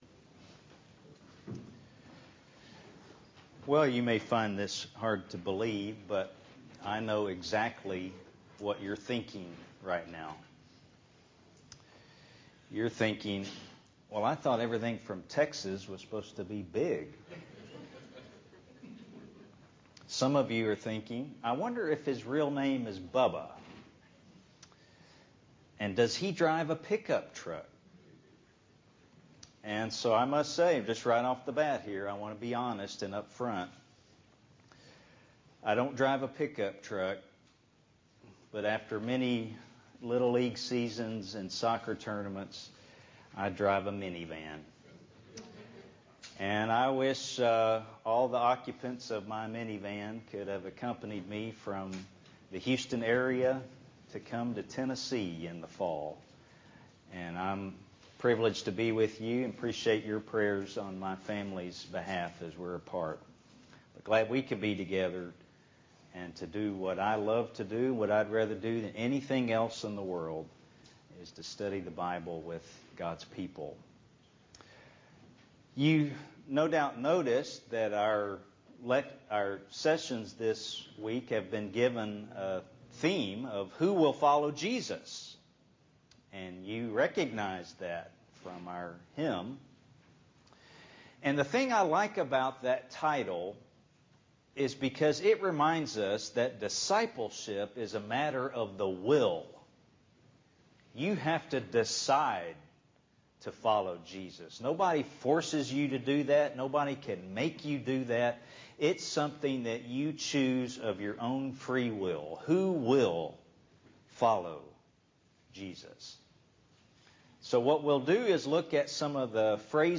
Disciples Work For The Master (Bible class)